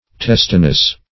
Testiness \Tes"ti*ness\, n.